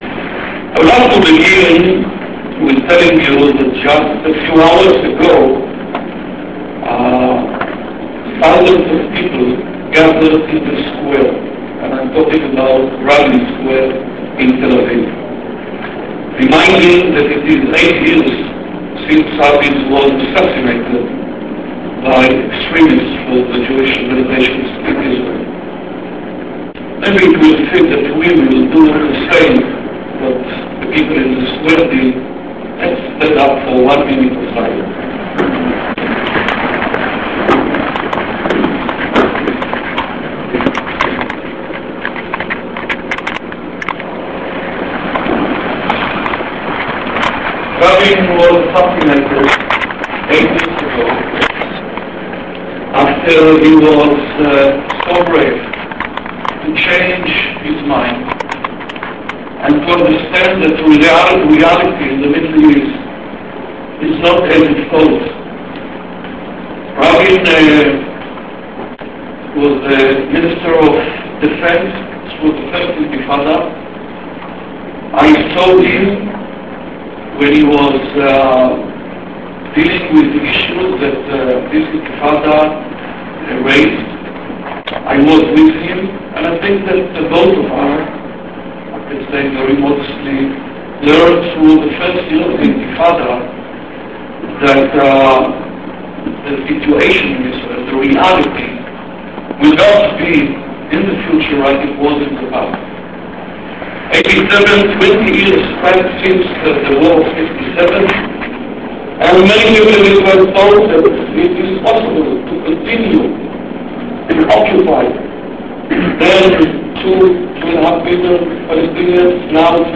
Everyone who deeply cares about Israel's future (and Palestine's) should have attended the speech given by Amram Mitzna on November 1st in Boston.
[ Printable PDF booklet ] The audio is mostly clear, and the text is pretty accurate, so playing the audio while reading the text is a richer experience than his Op-ed in the Boston Globe .